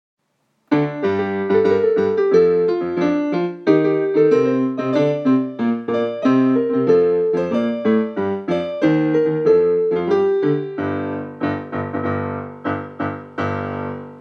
福岡ソフトバンクホークス #1 内川聖一 応援歌